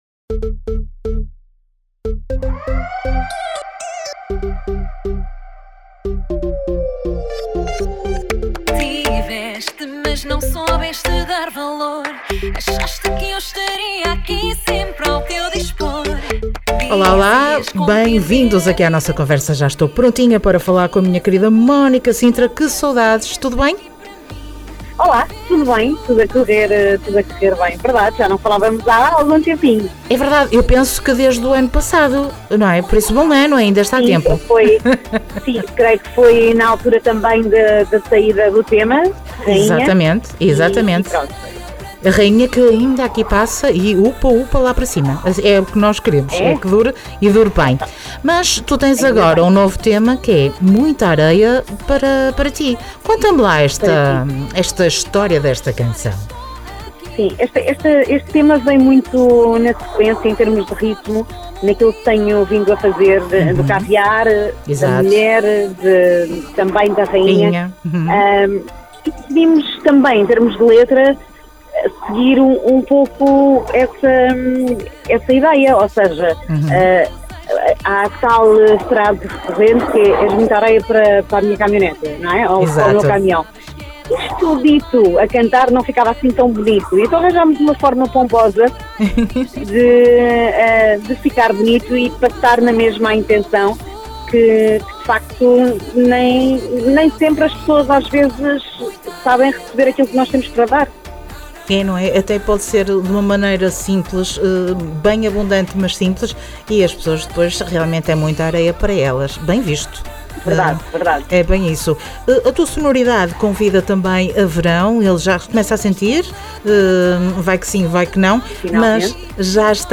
Entrevista Mónica Sintra 02 de Maio 2025